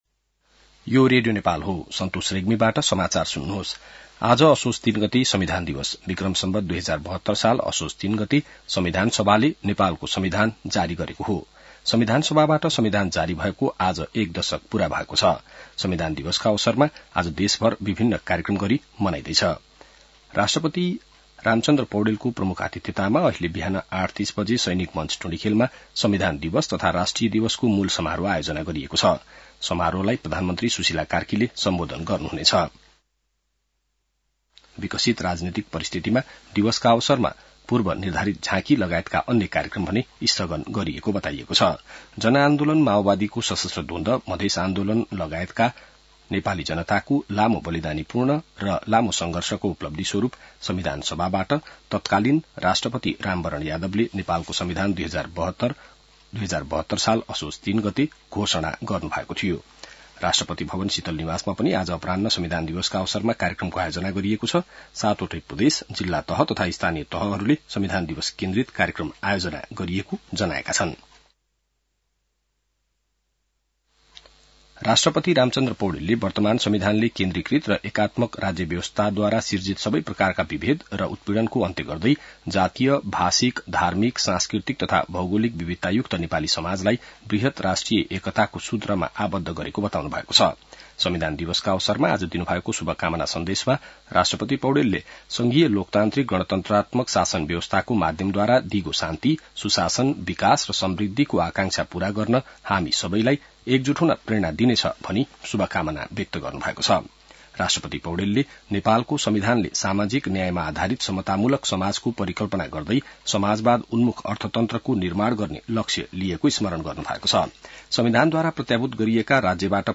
बिहान ६ बजेको नेपाली समाचार : ३ असोज , २०८२